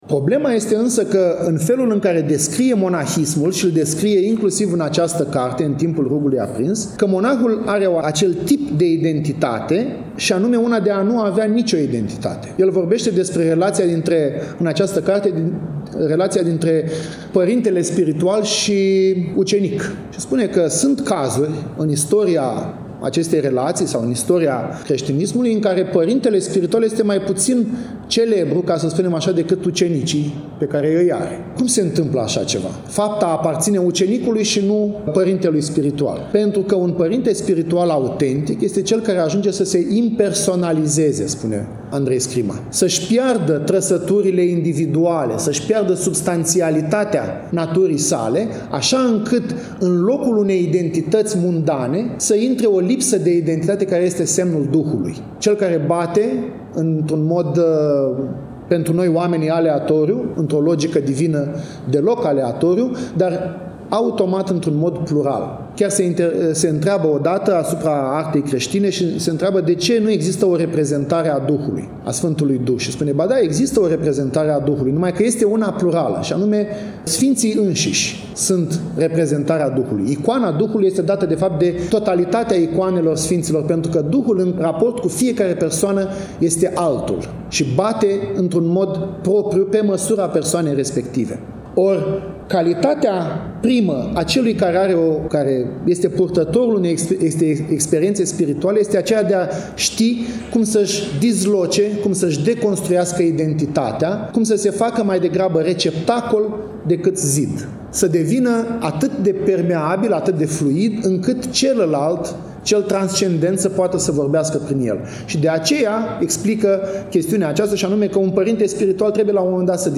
Conferința s-a desfășurat în ziua de vineri, 12 decembrie 2025, începând cu ora 13, în sala „B. P. Hasdeu”.